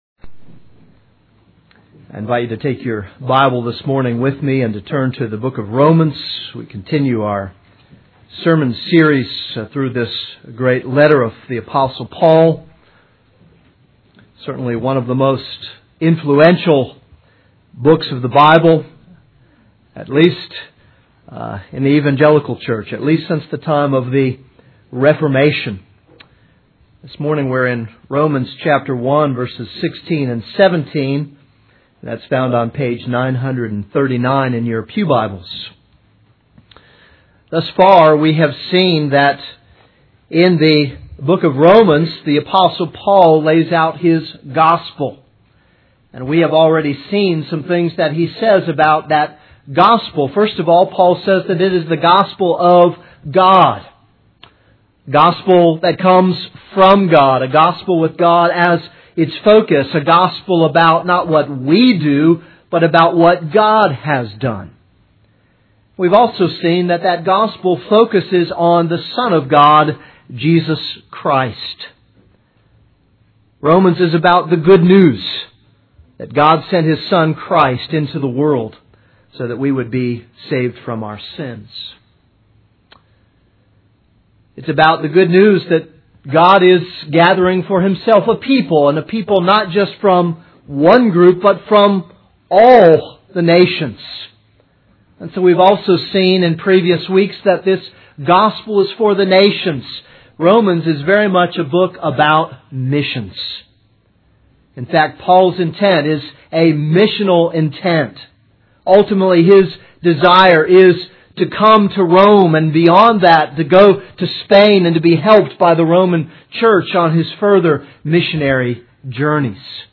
This is a sermon on Romans 1:16-17.